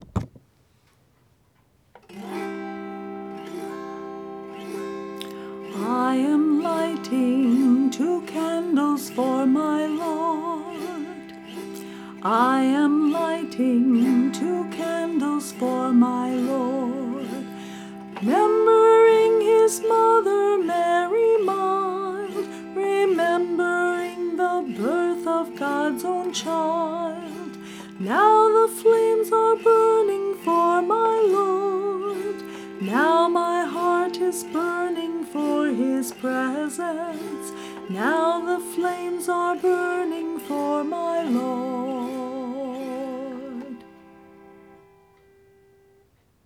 This entry was posted in Christian Music, Uncategorized and tagged , , , , , , , , , .